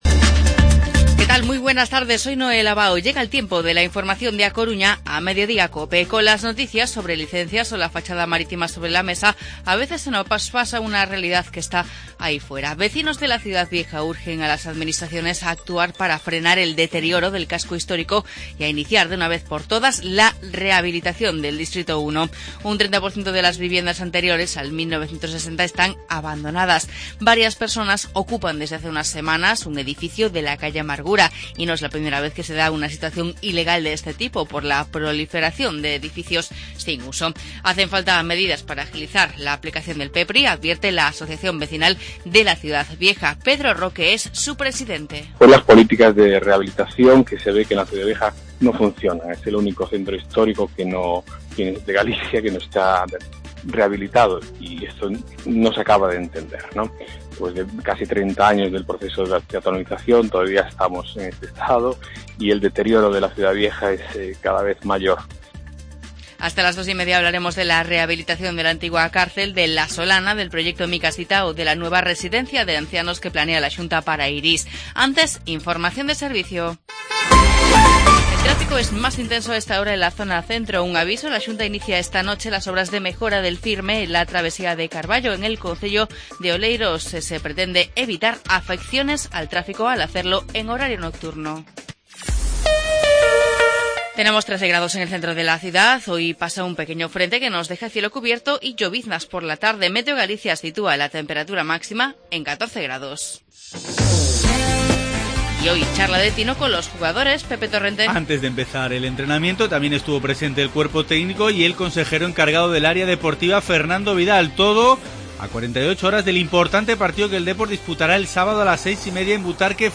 Informativo Mediodía COPE Coruña jueves, 23 de febrero de 2017